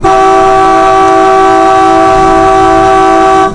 kamaz_horn.wav